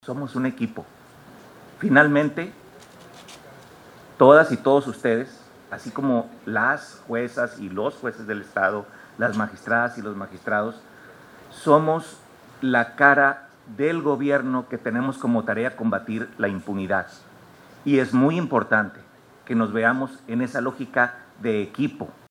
A su vez, el presidente del Supremo Tribunal de Justicia, Jesús Iván Chávez Rangel, a petición del gobernador Rocha, también se dirigió a los nuevos egresados de la UNIPOL, y destacó la importancia de tecnificar esta labor tan sensible que les toca realizar a ministerios públicos, investigadores y peritos.